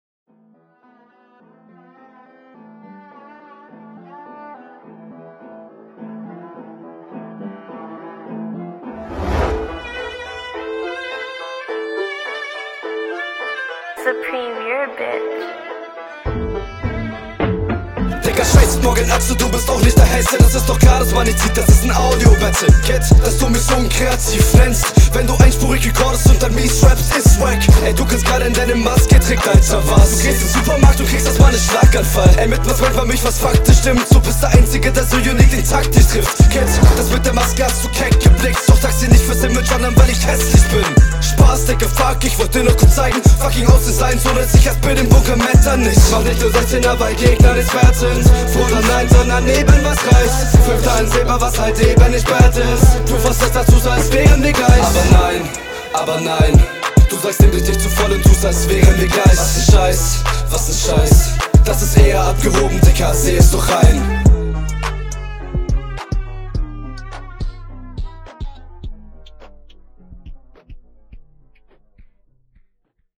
Mische ist super super clean. Ich habe ALLES verstanden